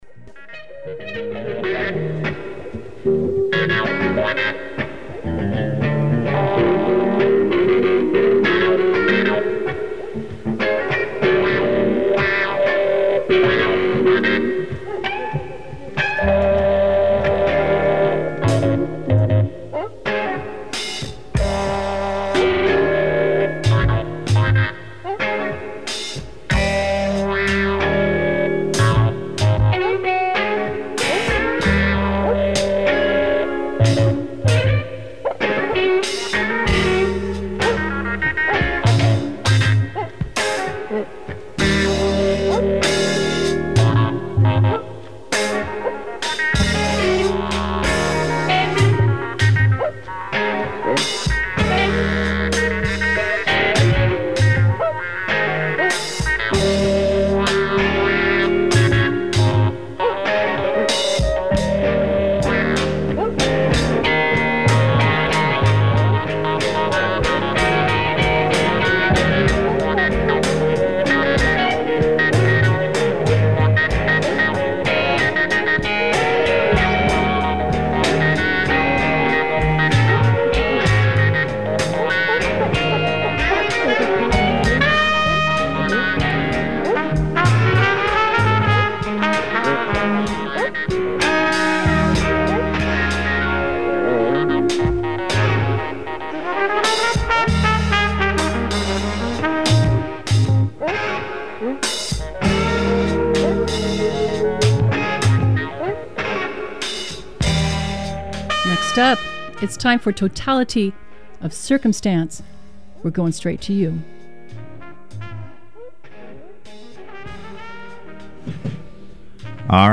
Live interview with members of PSU Student Union about the Disarm PSU campaign